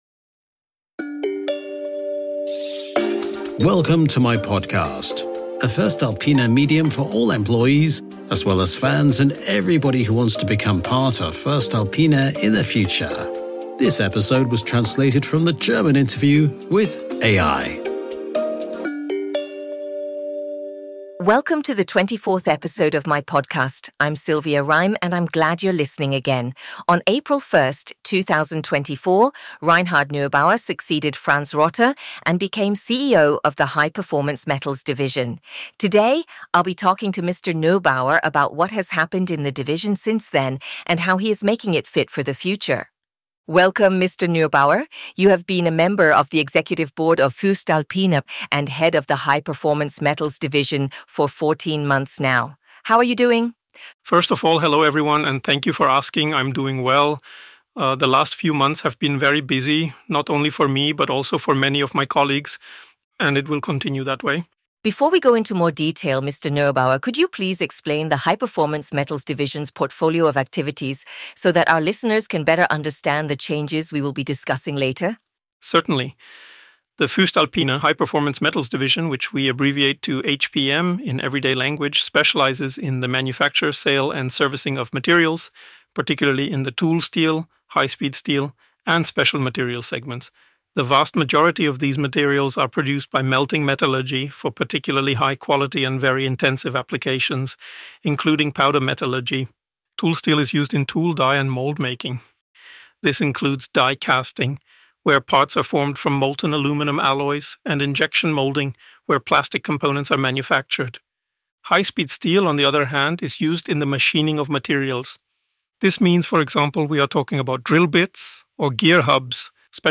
At the center of the conversation is the reorganization program, which promotes a new mindset and way of working across the division. This episode has been translated into English using AI from their original conversation in German and the voices were also artificially generated. myPODCAST is a voestalpine medium for all employees, but also for fans of voestalpine and future colleagues. myPODCAST is published on the first Wednesday of every month.